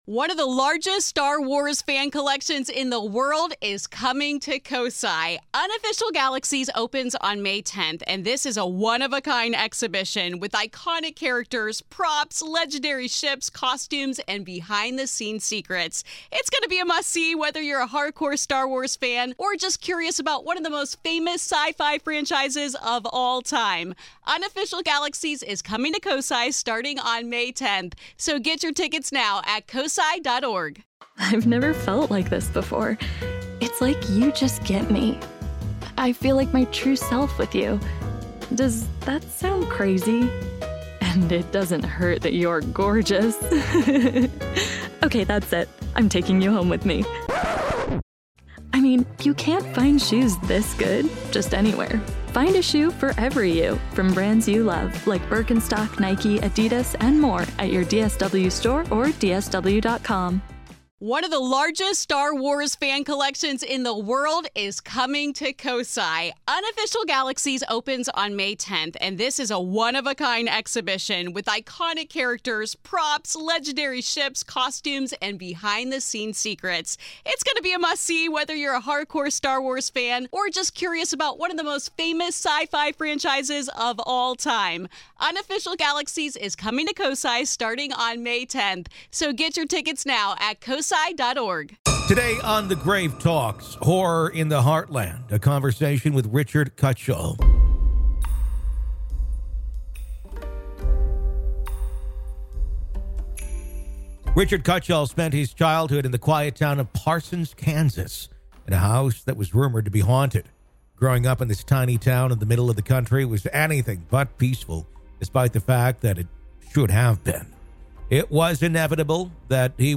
Interview The Grave Talks